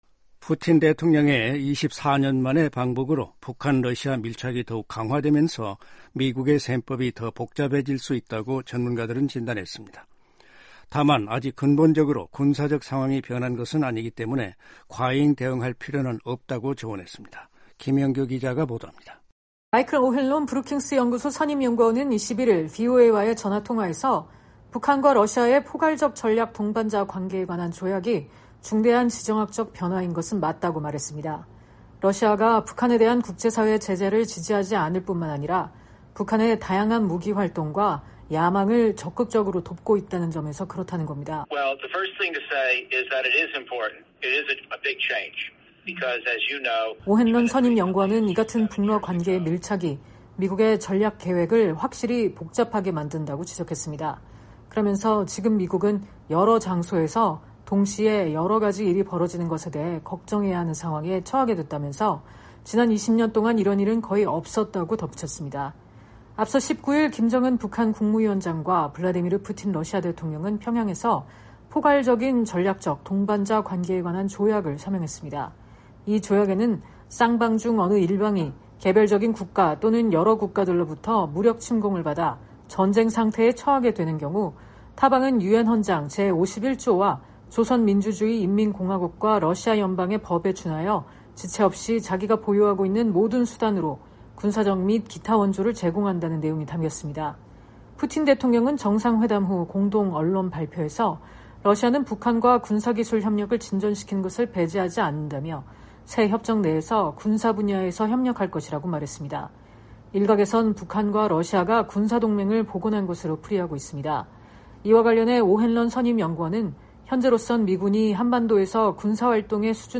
마이클 오핸런 브루킹스연구소 선임연구원은 21일 VOA와의 전화 통화에서 북한과 러시아의 ‘포괄적 전략 동반자 관계에 관한 조약’이 중대한 지정학적 변화인 것은 맞다고 말했습니다.
로버트 갈루치 전 국무부 대북특사는 21일 VOA와의 전화통화에서 “현 시점에서 미국이 긴장을 고조시킬 필요가 없다”고 말했습니다.